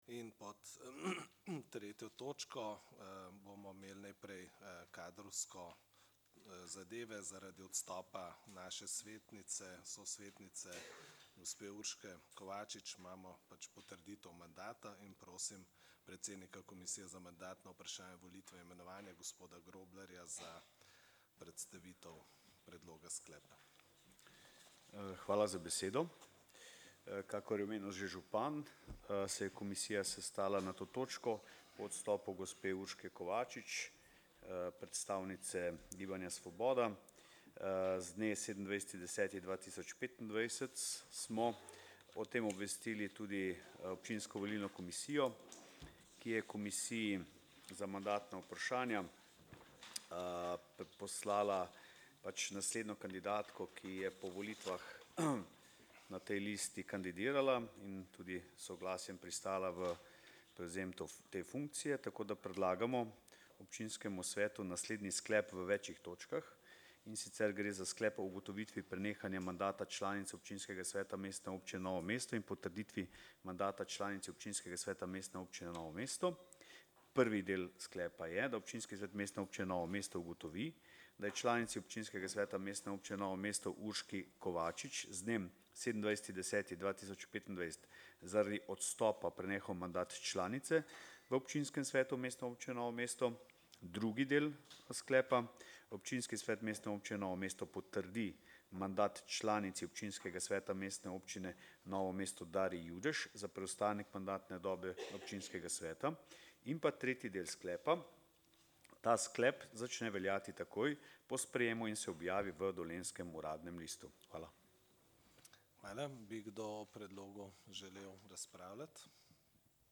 26. seja Občinskega sveta Mestne občine Novo mesto
Datum in ura: 18.12.2025 ob 16:00Lokacija: Mestna hiša, Glavni trg 7, Novo mesto